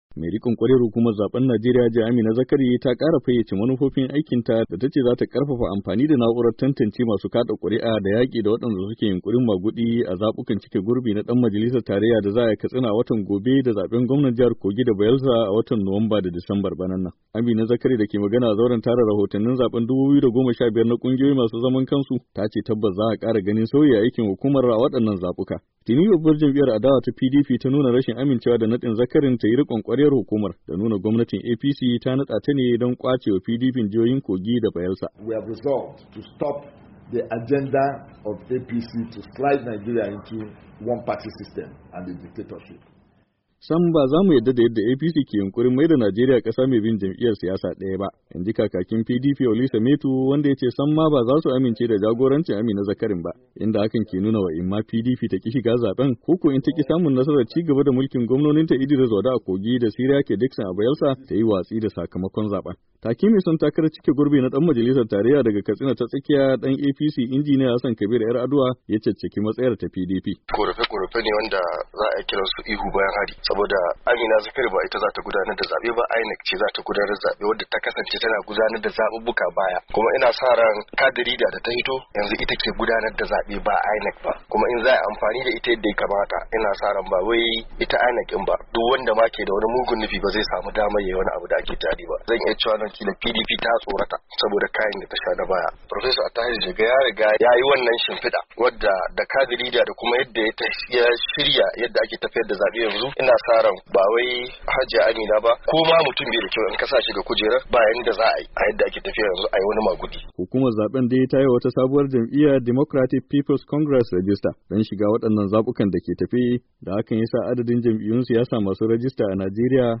Ga karin bayani a rahoton wakilin Muryar Amurka